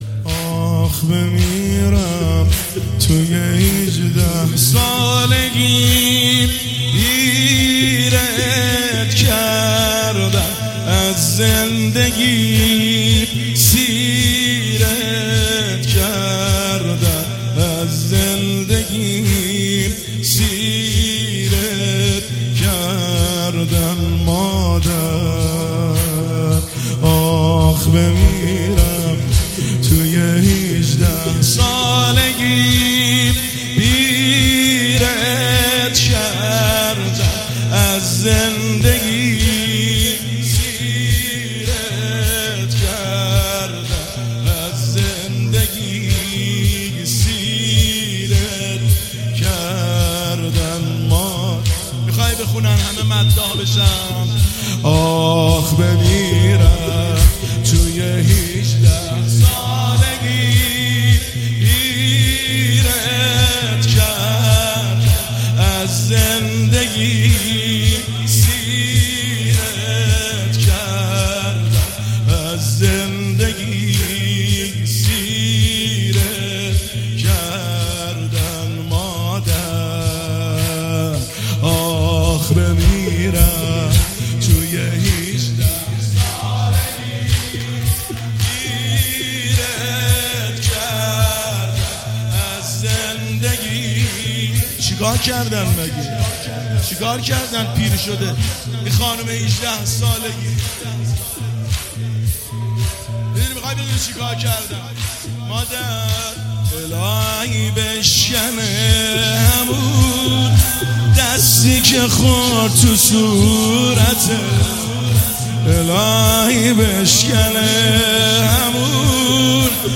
زمینه  ایام فاطمیه دوم 1404
هیئت محبان ثارالله جنت آباد تهران